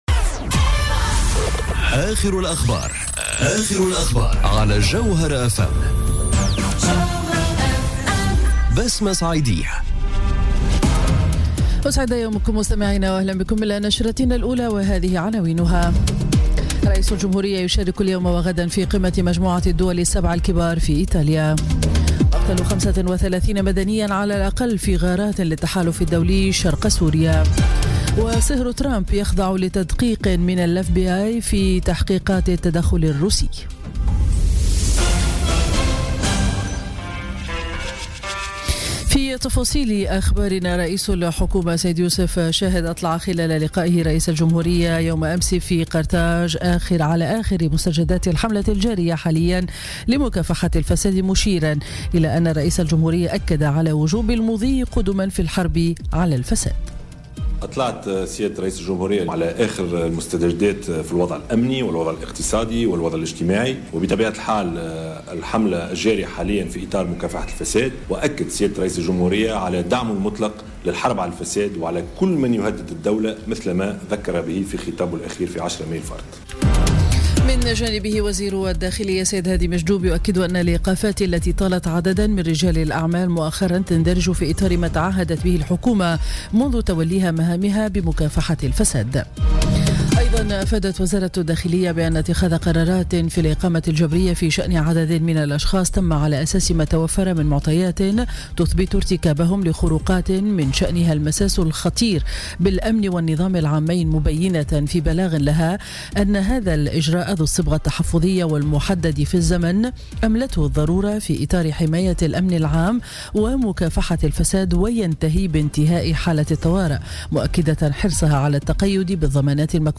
نشرة أخبار السابعة صباحا ليوم الجمعة 26 ماي 2017